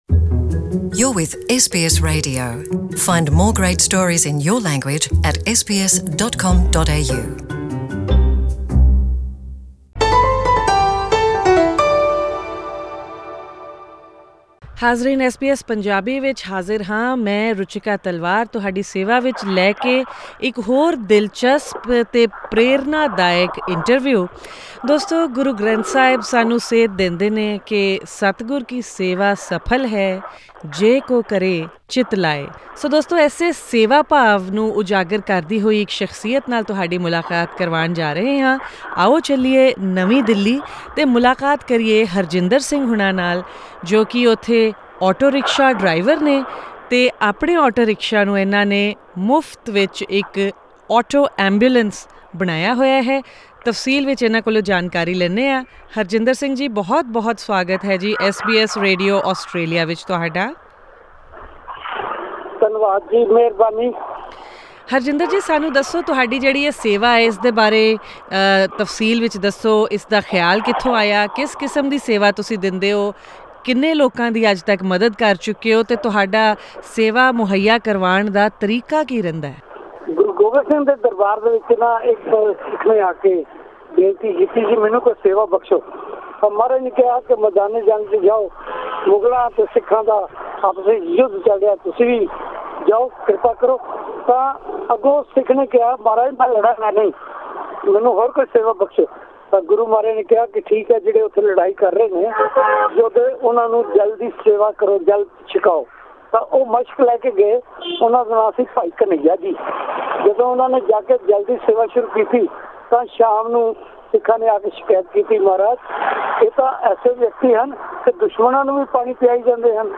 as he talks to SBS Punjabi over the telephone from New Delhi.